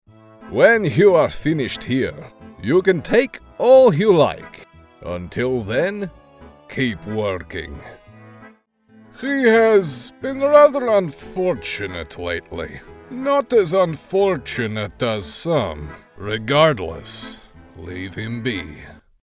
russian | character
Russian_Demo.mp3